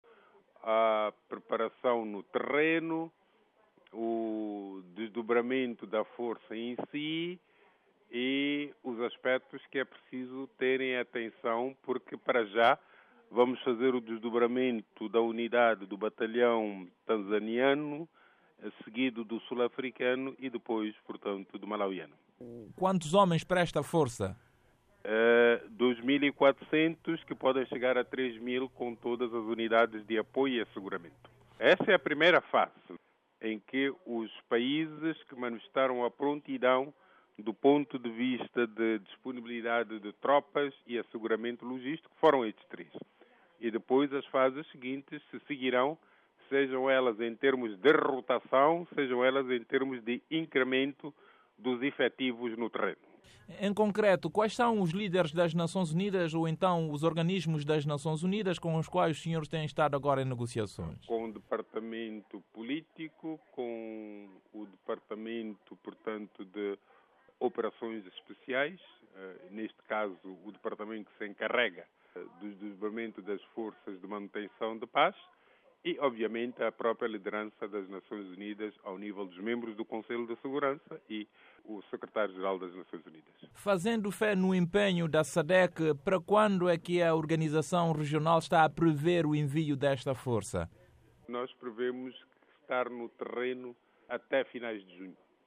Entrevista com Tomaz Salomão - 01:37